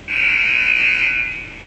klaxon.wav